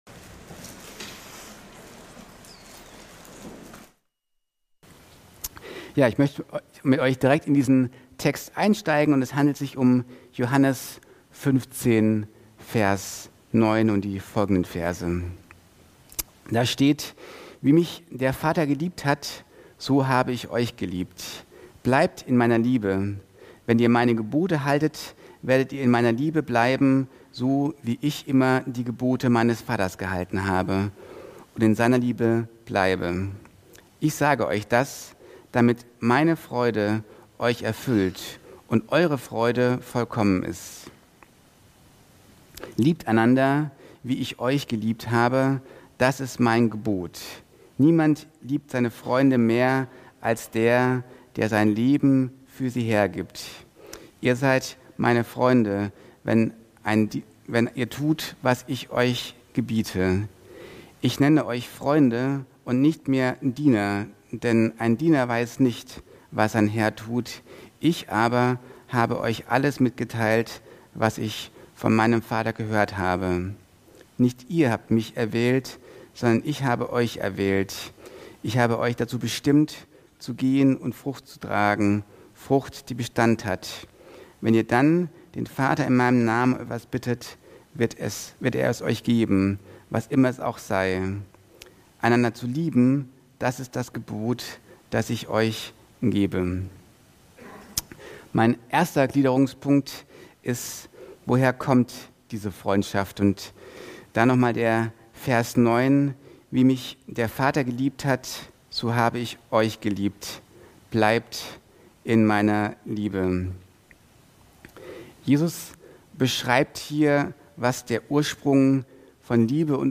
Dateien zum Herunterladen Update Predigt als MP4